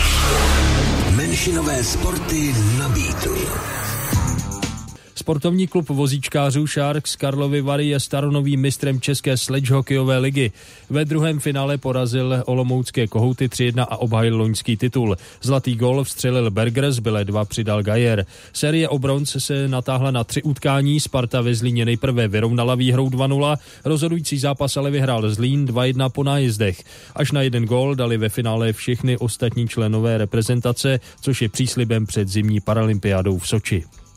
Reportáž z finálových bojů ČSHL